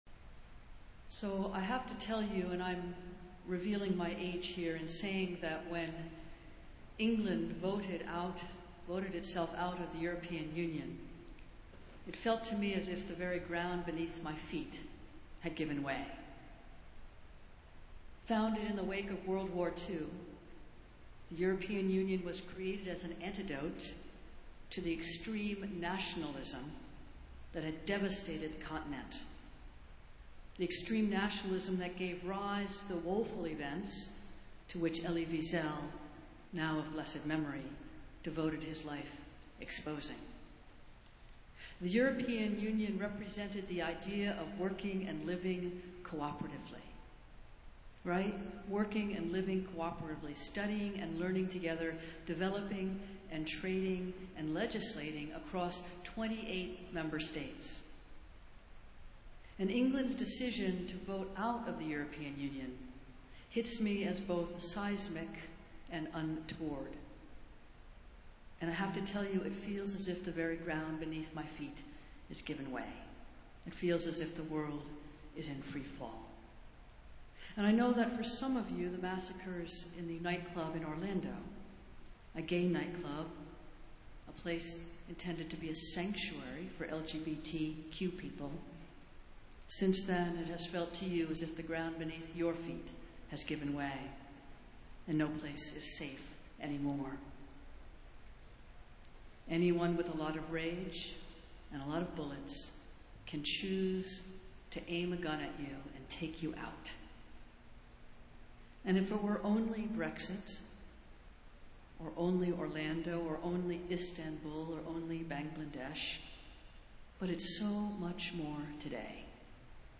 Festival Worship - Seventh Sunday after Pentecost